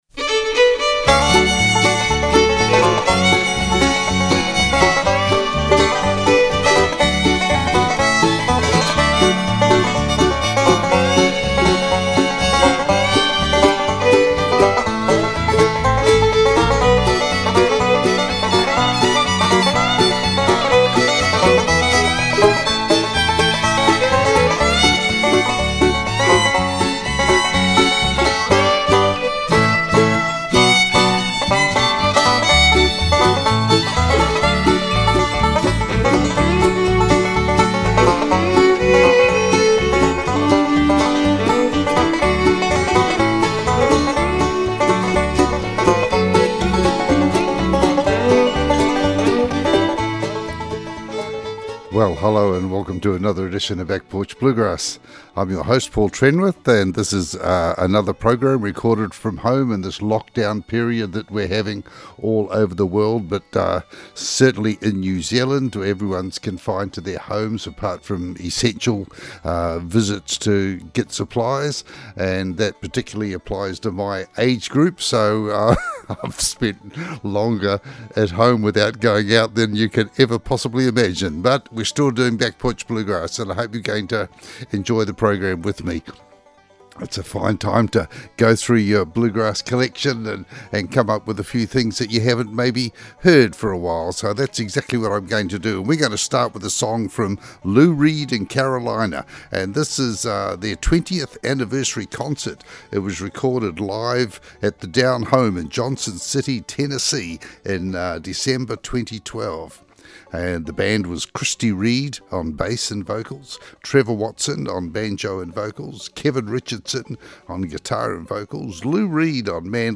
Back Porch Bluegrass Show